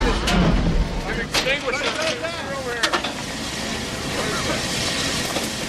Ambience Free sound effects and audio clips
workers chattering with pickhammer.ogg
[workers-chatterlng-sound-effect]_ngi.wav